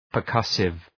Προφορά
{pər’kʌsıv}